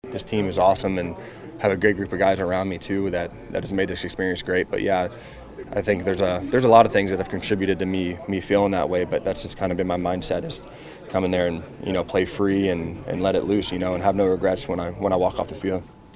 Skylar Thompson had an outstanding camp and it carried over into the preseason games, where he was the Steelers’ most effective quarterback.  Thompson says he has had a great time at camp.
nws0586-skylar-thompson-i-am-great.mp3